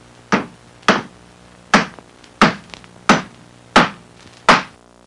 Hammering Sound Effect
Download a high-quality hammering sound effect.
hammering-1.mp3